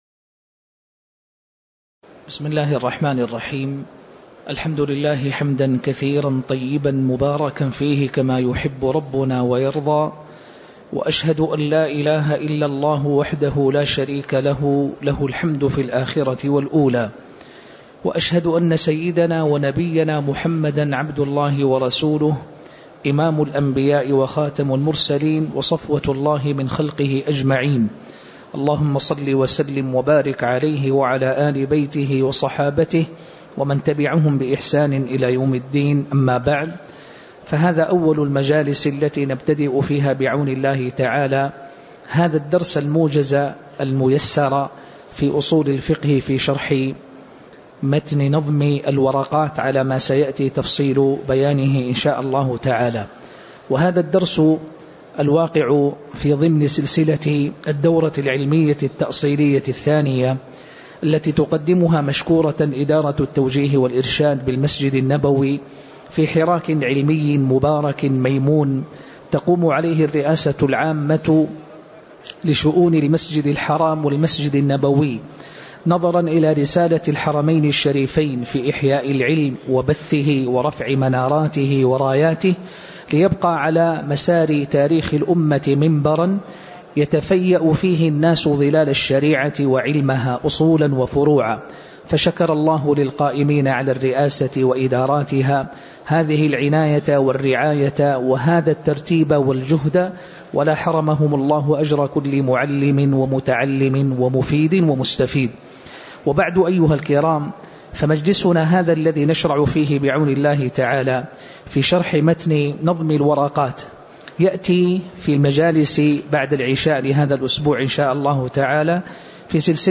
تاريخ النشر ٩ شوال ١٤٣٩ هـ المكان: المسجد النبوي الشيخ